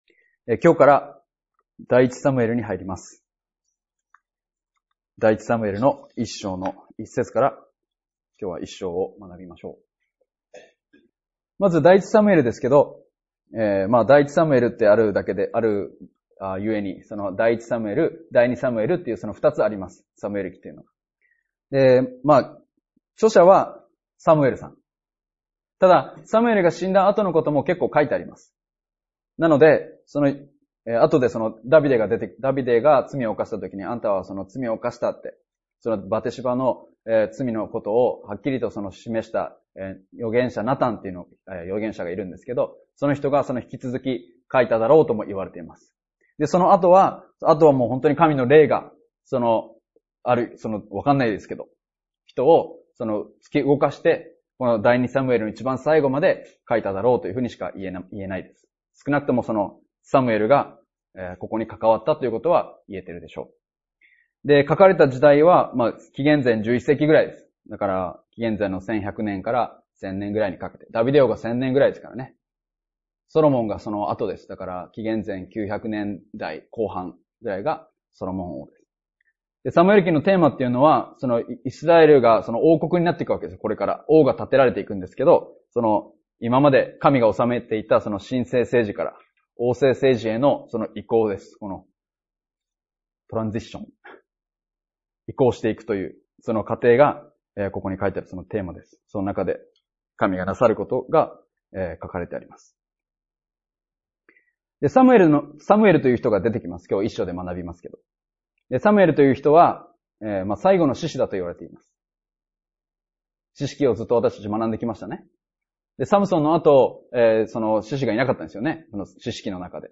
礼拝やバイブル・スタディ等でのメッセージを聞くことができます。